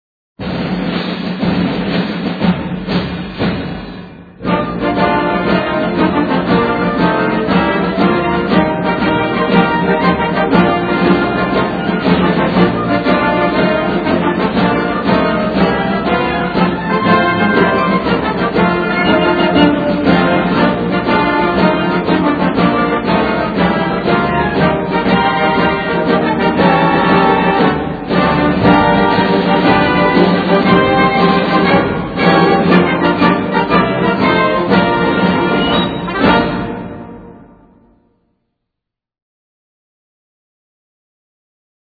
Since most theme music for colleges orginated in the 1920s and 30s, the sound is a traditional one with modernist tweaks: military band arrangements with ragtimey accent, typically played very quickly, often ripped through at lightspeed following big plays in games.
Both are stirring, fun, and almost make you want to put on a uniform.